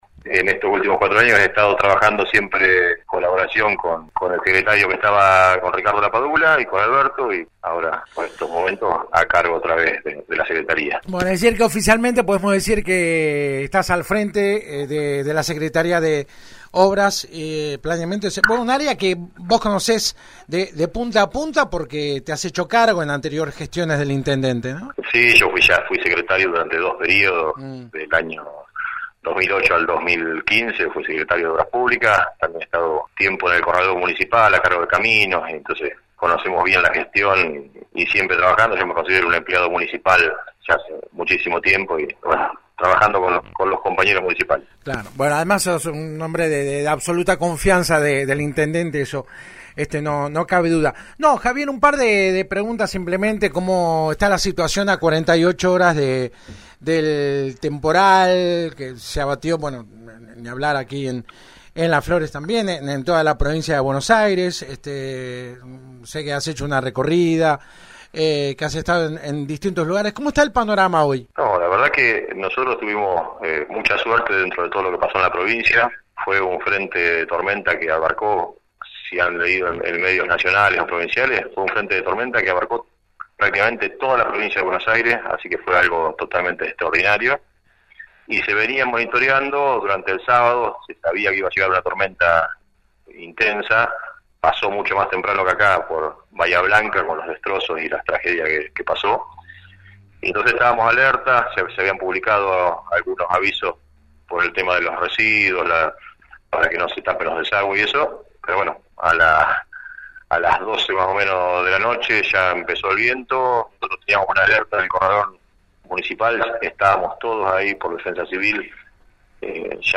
Si bien en los últimos cuatro años integró dicha área, el Ing. Arreyes se hizo cargo esta semana de manera oficial de la Secretaría de Obras, Planeamiento y Servicios Públicos. En su primera entrevista, el funcionario habló con la 91.5 sobre diversos temas como la continuación de la Autovía en ruta 3, los tiempos que vienen, estado del parque vial y lo que dejó el temporal del pasado fin de semana.